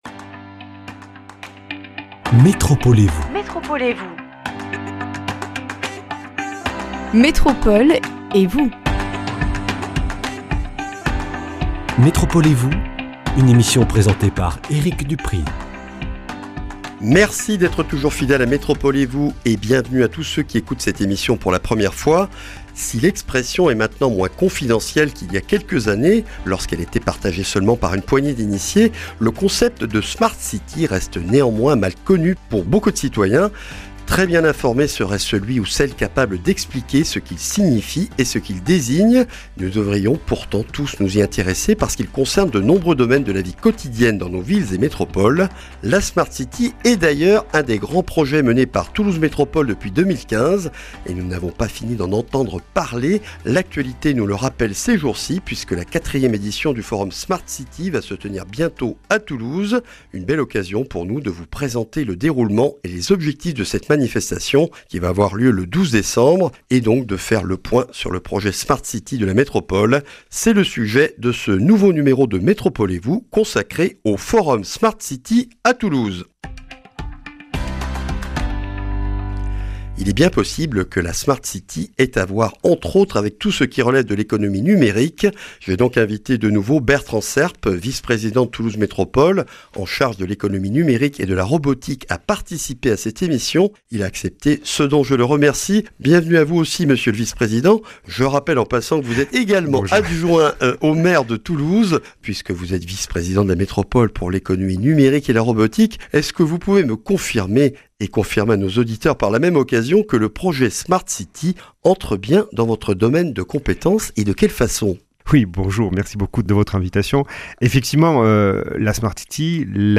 À la découverte du Forum Smart City qui se tient à Toulouse mercredi 12 décembre avec notre invité, Bertrand Serp, adjoint au maire de Toulouse, Vice-président de Toulouse Métropole en charge de l’Économie numérique et de la Robotique.